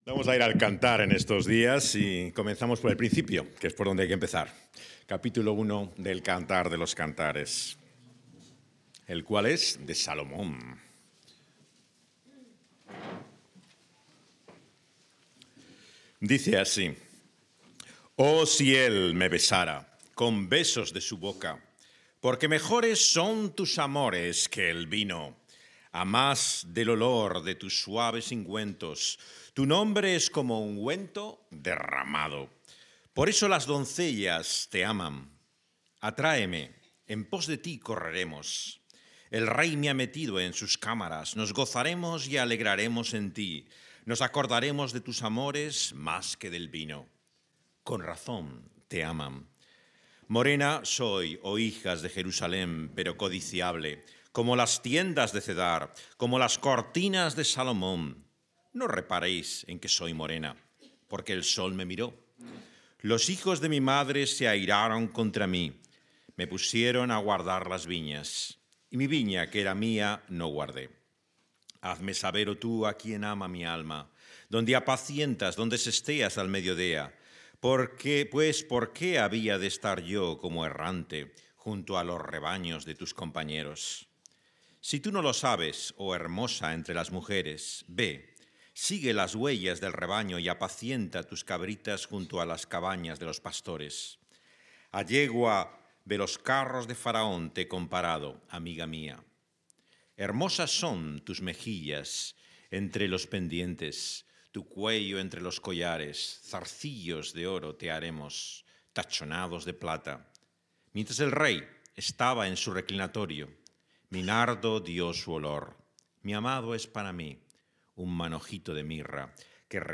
grabado en O Carballi˜o